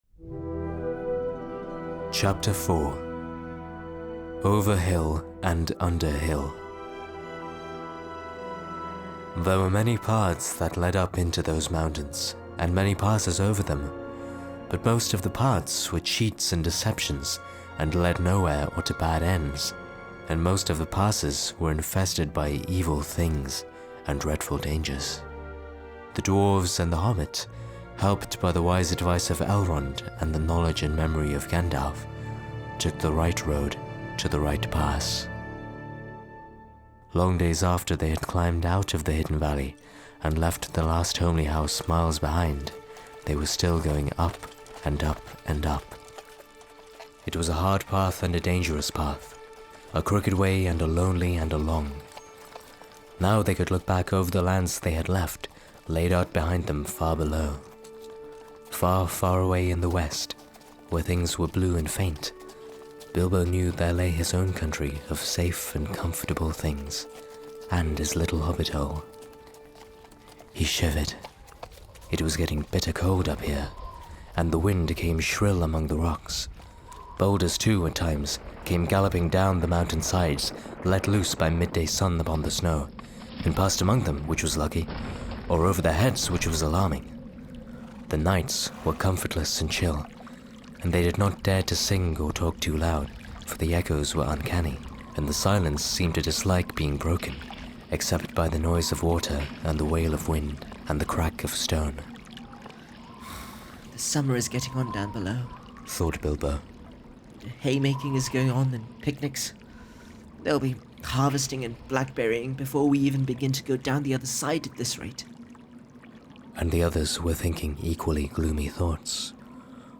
Lord Of The Rings And Hobbit Audiobooks (J.R.R. Tolkien)! Podcast - The Hobbit - Chapter 4: Over Hill and Under Hill (J.R.R. Tolkien) | Free Listening on Podbean App